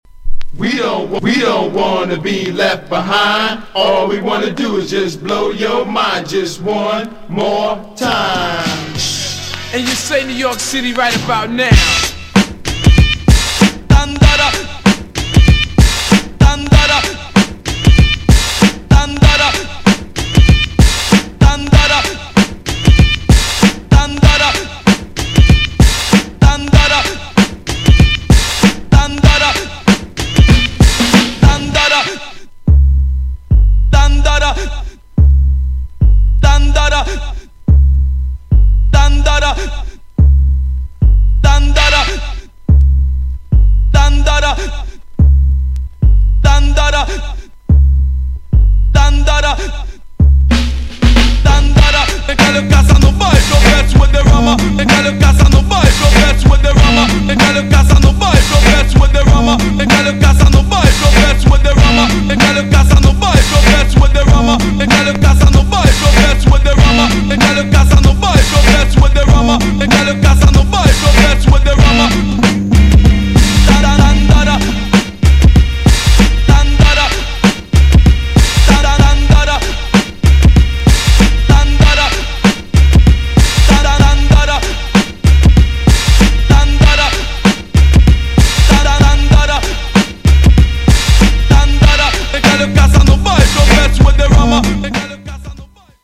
オールドスクールネタのTRACK物の
GENRE Hip Hop
BPM 101〜105BPM